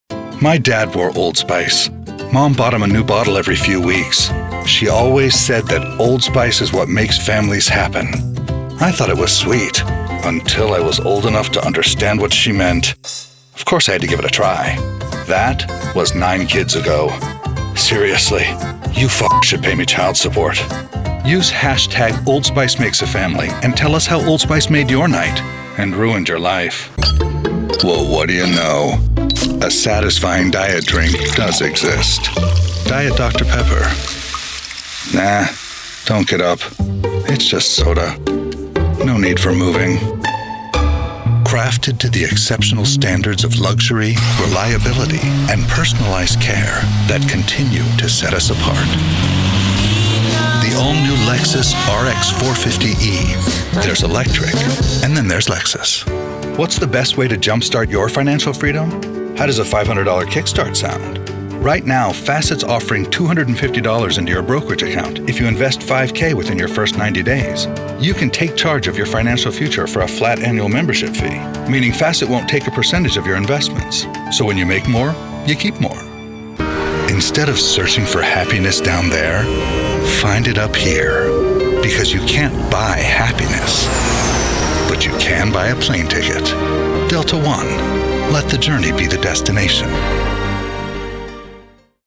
Professional Male Voice Actor